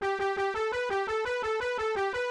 won.wav